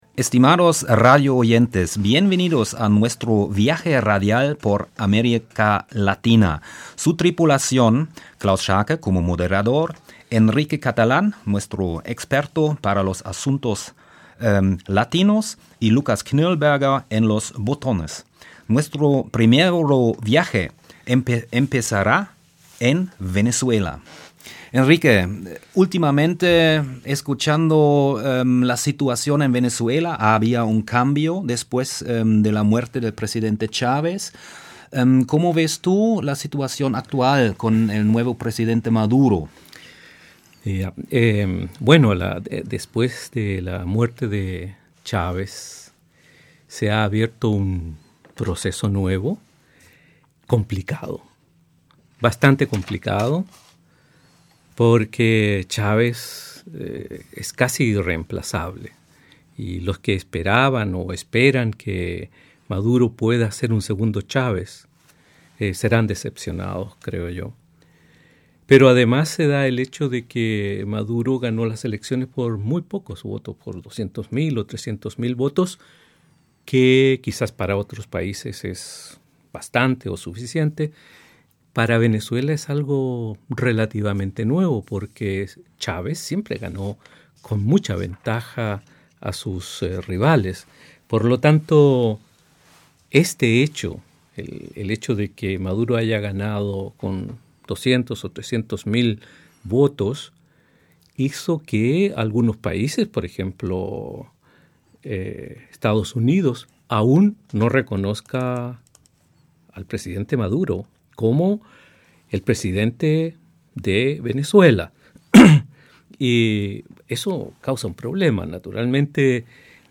Trotz der vielen Schwierigkeiten und Widersprüche, die sich aktuell darstellen, kann dieses Land ein Modell für eine würdigere Zukunft für die Bürger und den Subkontinent sein. Hier hören Sie die am 4. Oktober aufgezeichnete, spanischsprachige Freie-Radio-Sendung vom 10. Oktober 2013.